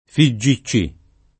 $ffe J& ©©i &+ o f& JJi ©©&+] n. pr. f. — sigla di Federazione Giovanile Comunista Italiana: correntem. letta con un anomalo fi+ al posto di $ffe, suggerito dalla sequenza degli altri nomi di lettere in -i, e omettendo l’I. finale, assorbito dal suono della lettera precedente: di qui l’agg. e s. m. figgiccino (meglio che figicino) o figgicciotto — la stessa lettura, sempre nell’uso corrente o meno accurato, per l’altra sigla F.I.G.C. [$ffe & JJi ©©&+ o f& JJi ©©&+], di Federazione Italiana del Giuoco del Calcio — casi simili, quelli di C.G.I.L. [©i JJ& i $lle o ©& JJi $lle] e di P.C.I. [p& ©©i &+ o pi ©©&+]